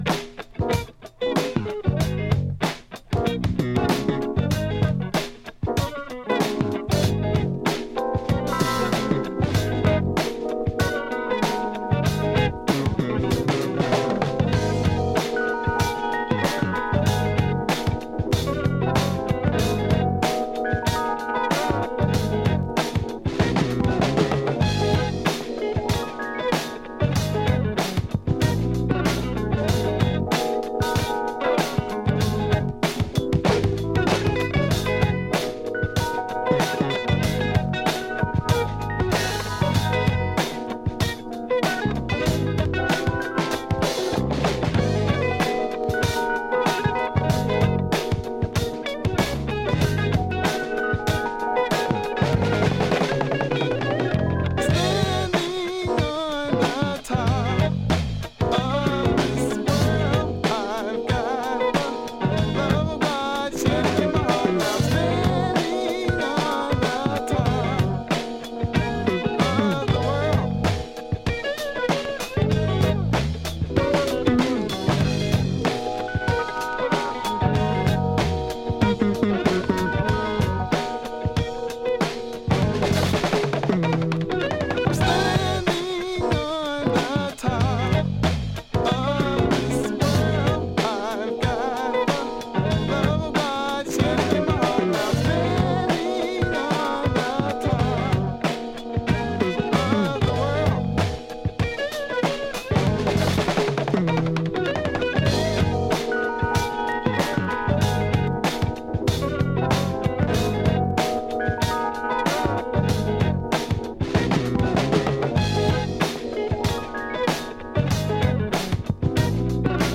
Boogie, Funk / soul